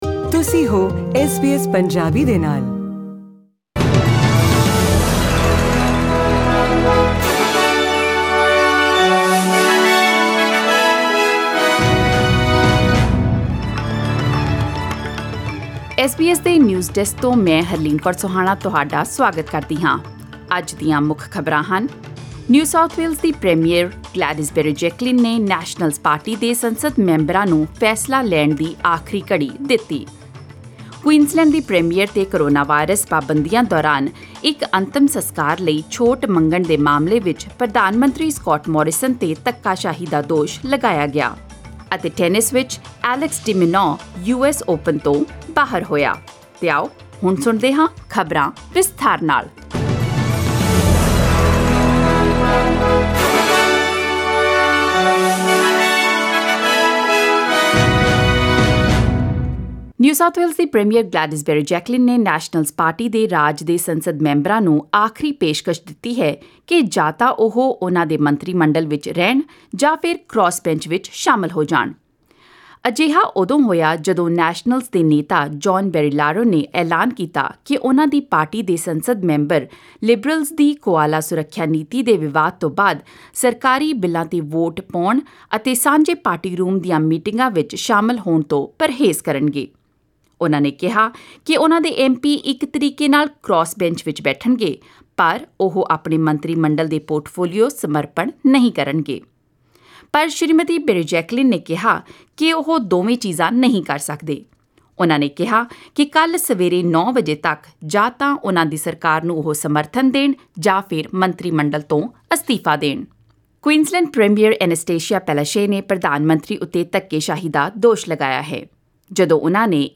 Presenting the major national and international news stories of today; sports, currency exchange rates and the weather forecast for tomorrow. Click on the player at the top of the page to listen to the news bulletin in Punjabi.